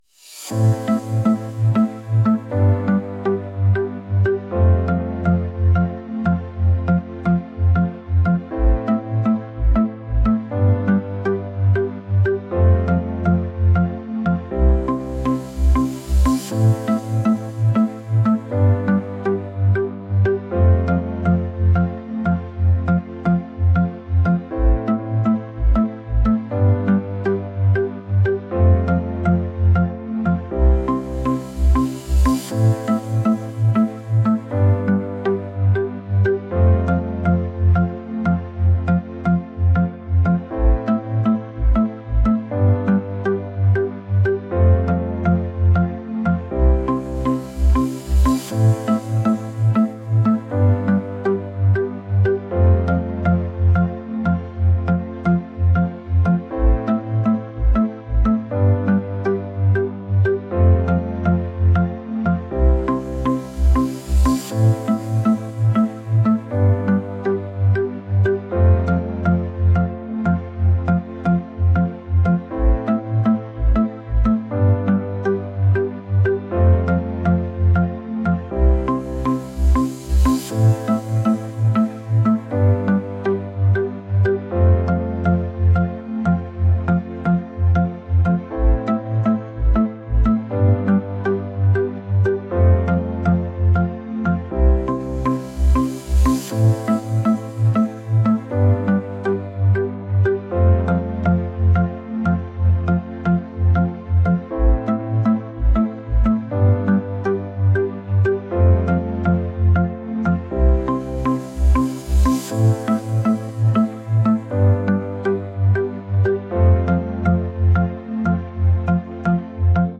pop | electronic | latin